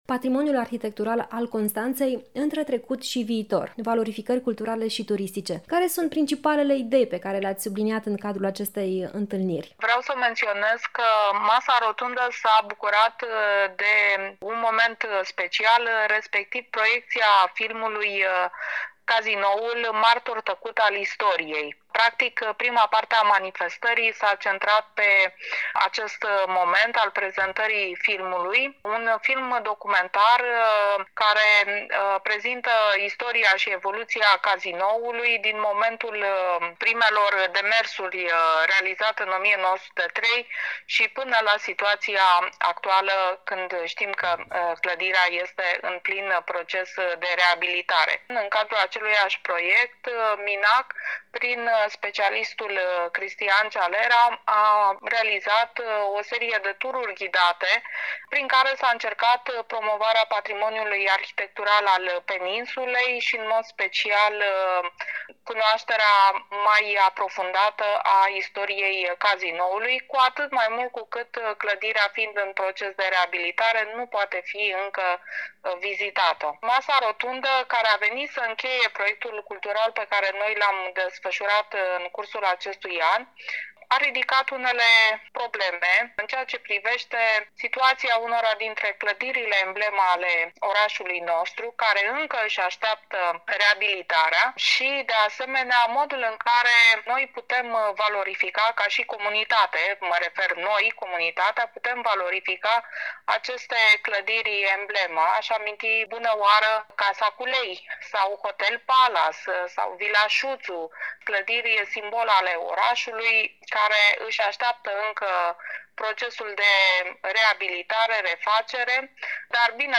Specialiștii au vorbit în cadrul unei dezbateri care a avut loc la Universitatea "Ovidius".